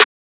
BWB X SUPA 2 - TRAVI SNARE.wav